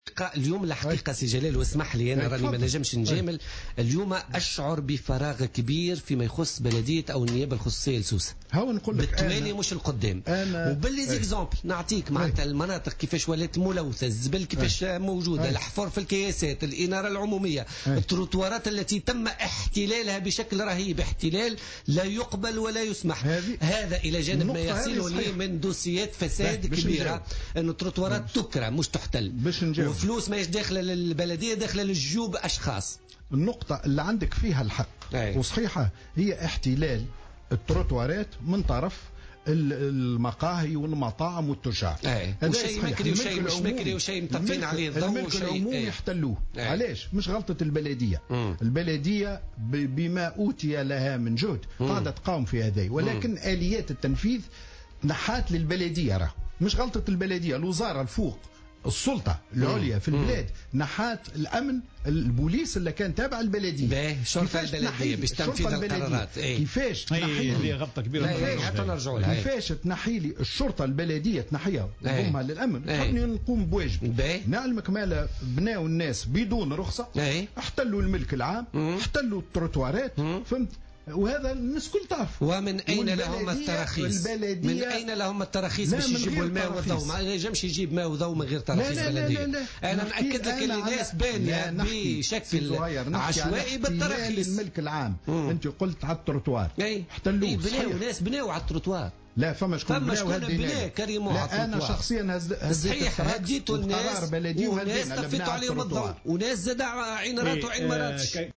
ونفى جلال تفيفحة، نائب رئيس النيابة الخصوصية لبلدية سوسة، ضيف برنامج "بوليتيكا" اليوم منح البلدية لأي تراخيص دون وجه حق، مؤكدا أن استفحال ظاهرة احتلال الملك العمومي ليست خطأ البلدية التي لم تعد لها آليات التنفيذ بعد فصل الشرطة البلدية عن البلديات.